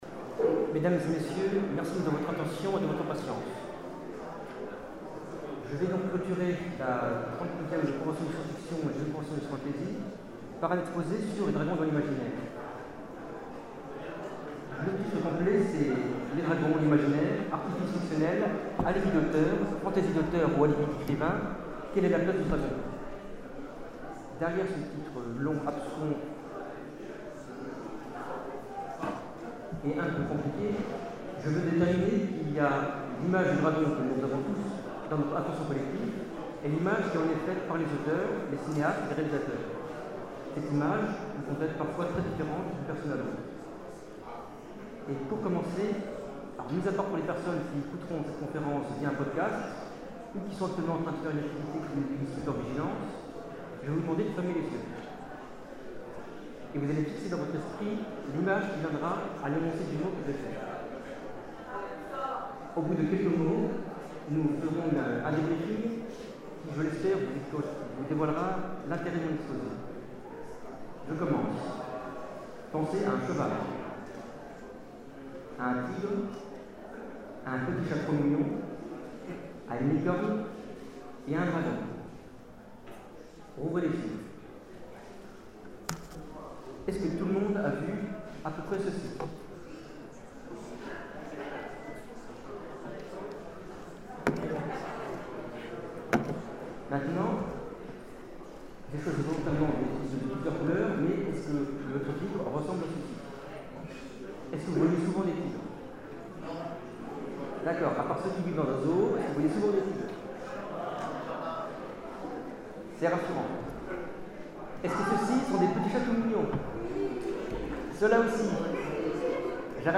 Convention 2012 : Conférence Les dragons en imaginaire : chimère ou lubie ?